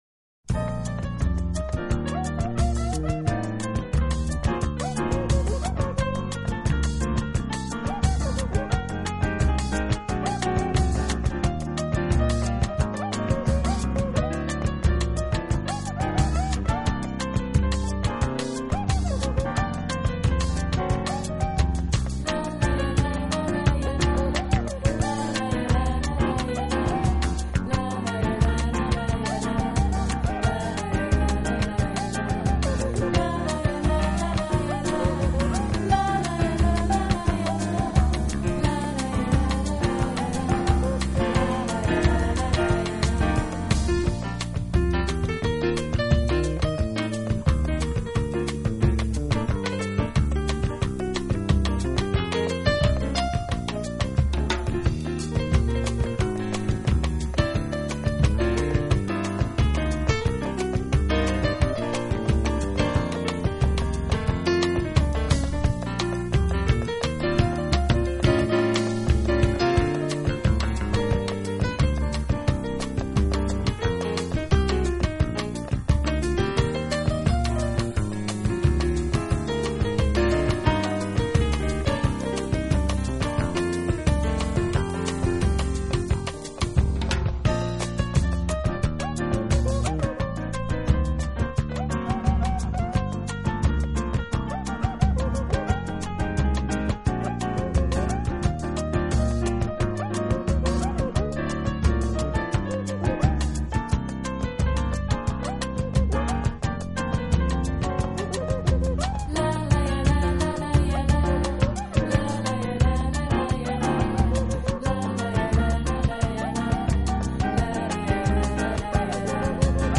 piano & percussion
drums & percussion